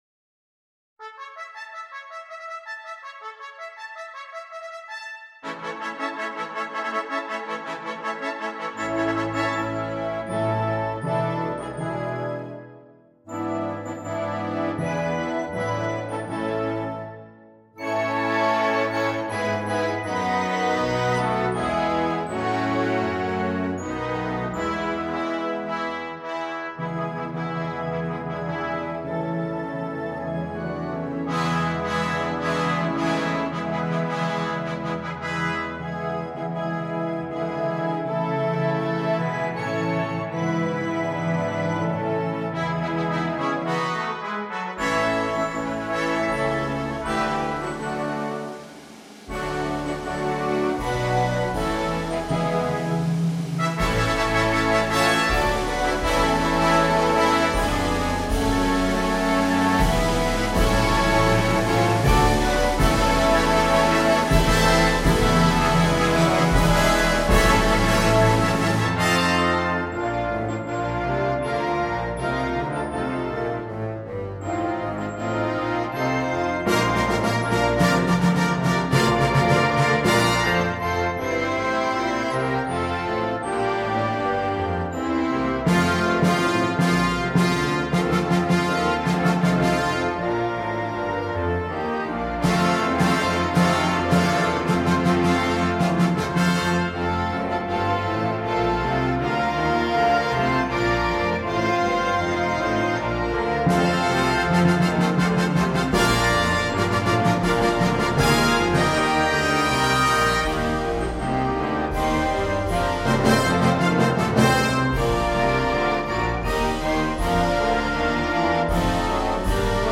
ist eine festliche Komposition
Besetzung: Concert Band